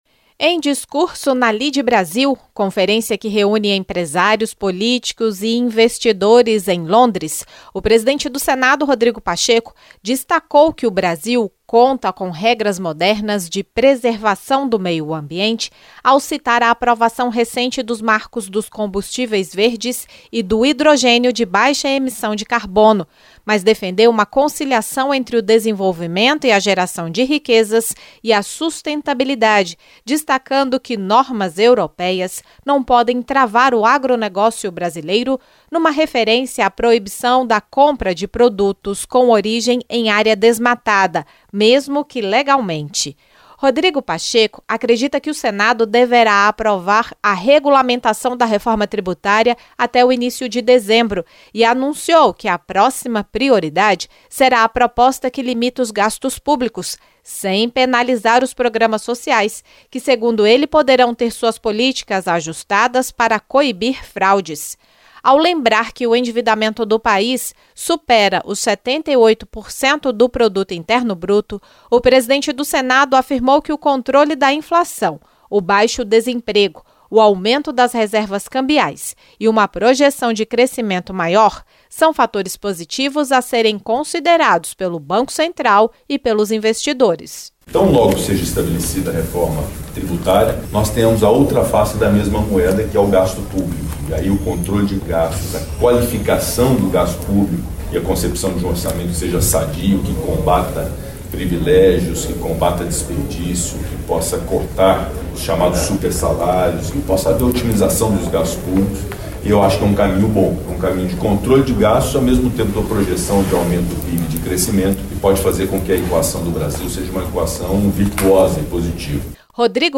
Em discurso na Lide Brazil em Londres, conferência que reúne empresários, políticos e investidores brasileiros e britânicos, o presidente do Senado, Rodrigo Pacheco, afirmou que a regulamentação da reforma tributária deverá ser aprovada até o início de dezembro e que os senadores deverão se dedicar a propostas de redução dos gastos públicos.